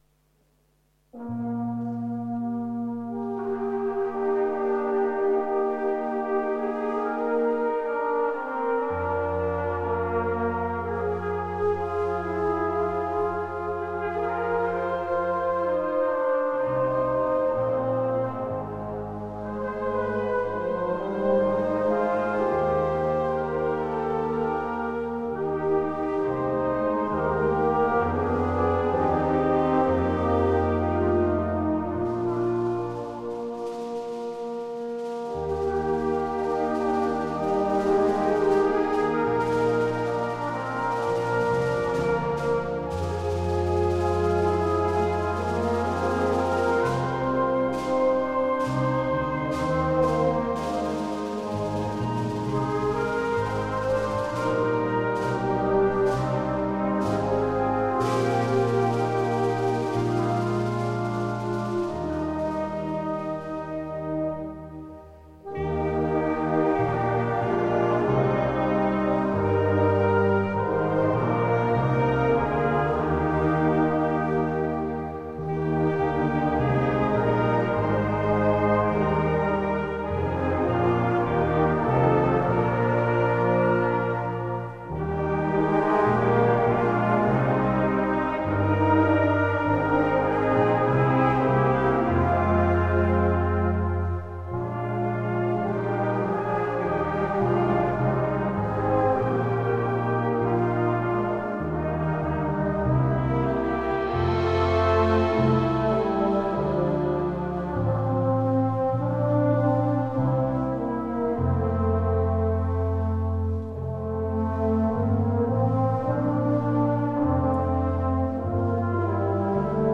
Konzert 2019